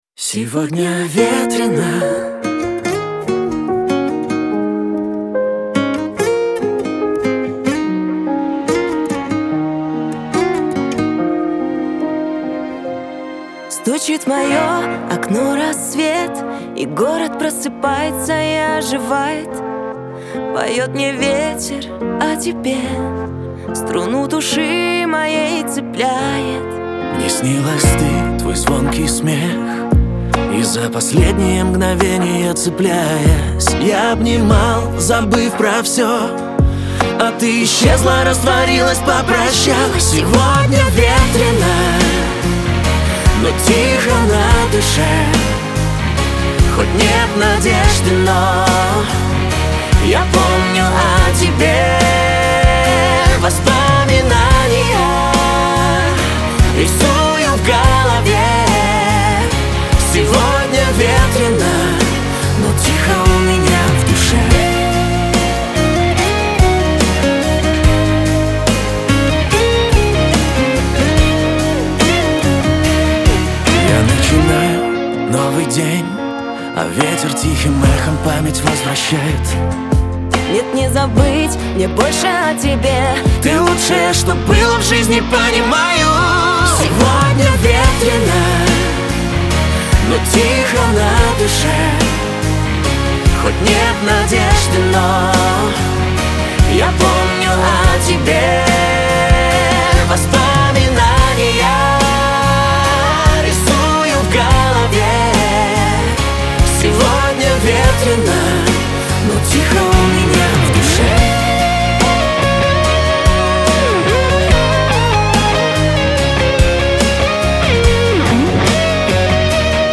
дуэт , pop
эстрада